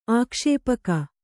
♪ ākṣēpaka